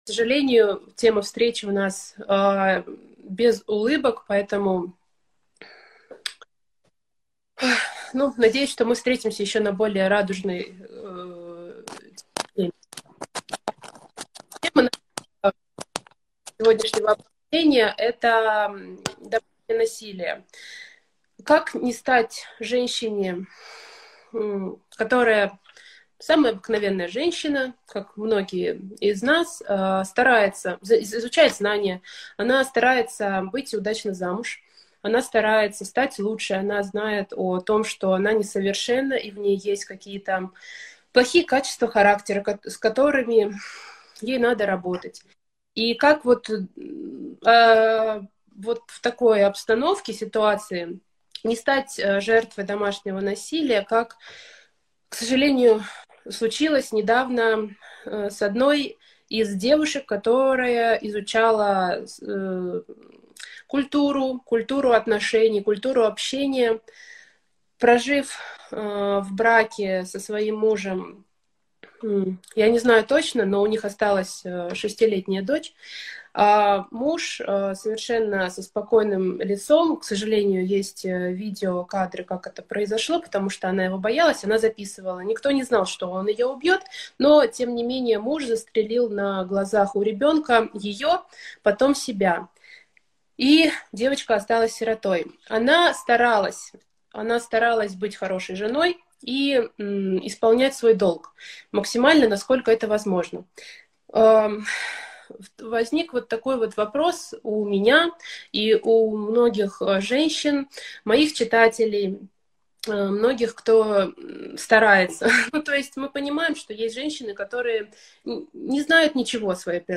Алматы
Беседа